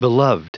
Prononciation du mot beloved en anglais (fichier audio)
Prononciation du mot : beloved